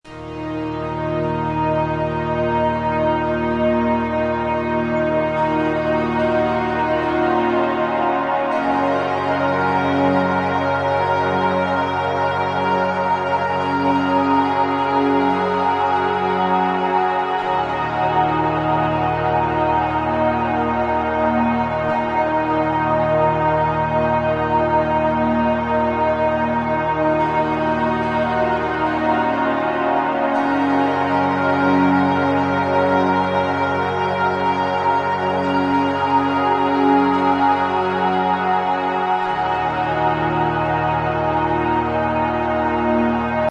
Download Sad sound effect for free.
Sad